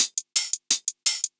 Index of /musicradar/ultimate-hihat-samples/170bpm
UHH_ElectroHatB_170-04.wav